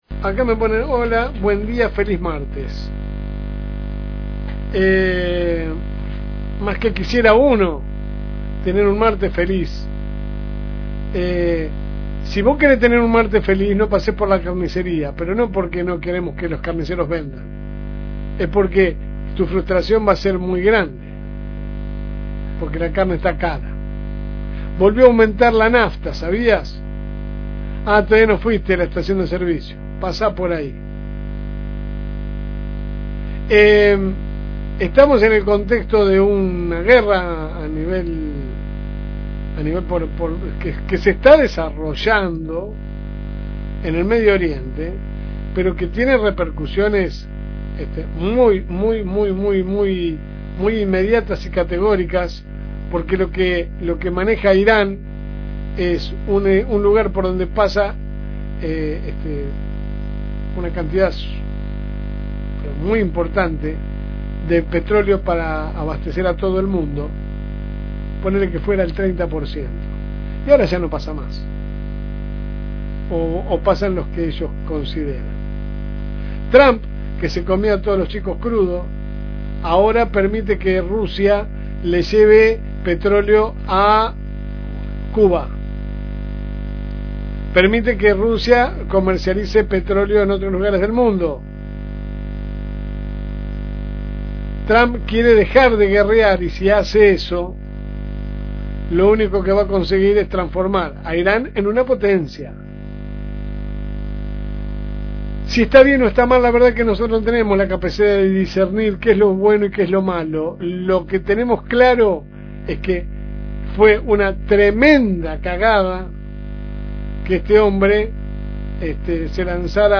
Editorial LSM
La editorial a continuación: